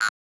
acid_break_091.ogg